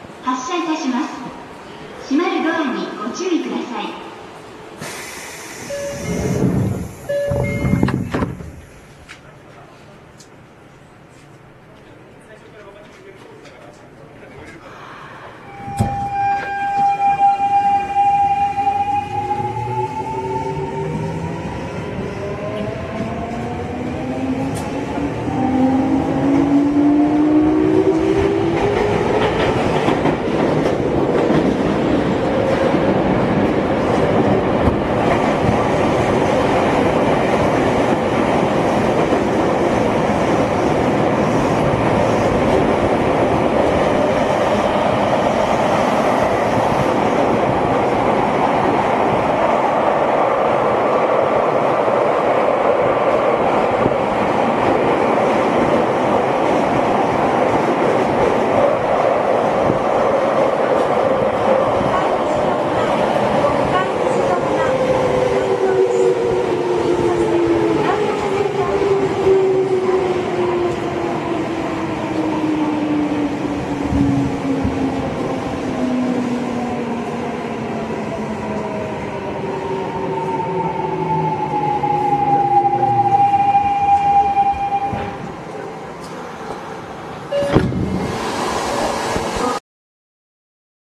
3レベルでは非同期モードがまるで日立GTO後期タイプのような音がしてかなり独特です。
走行音（3レベル）
収録区間：千代田線 霞ヶ関→国会議事堂前